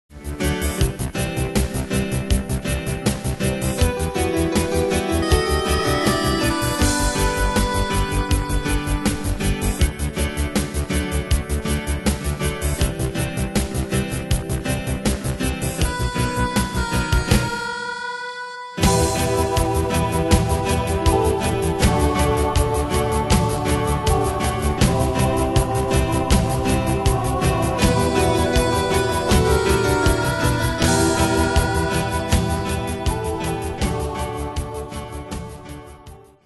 Style: PopFranco Ane/Year: 1992 Tempo: 80 Durée/Time: 3.55
Danse/Dance: PopRock Cat Id.